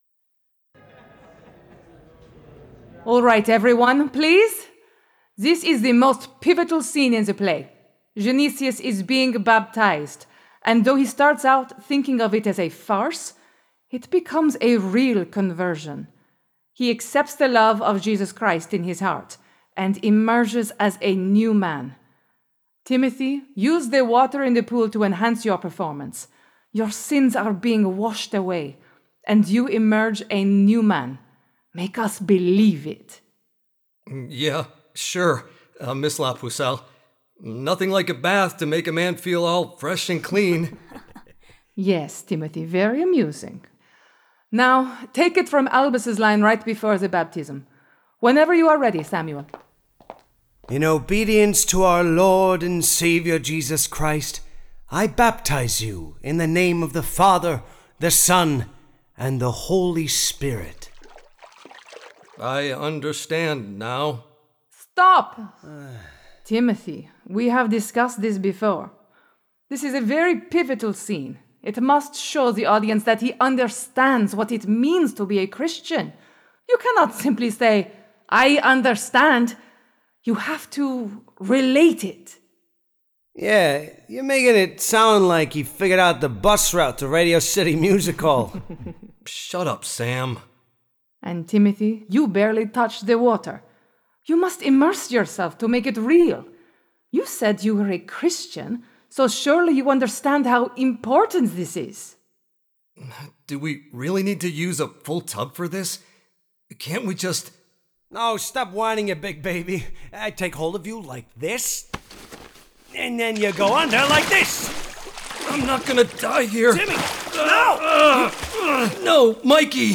Dramatized Stories of Art and Faith
• • Narrators: Full Cast Audio Drama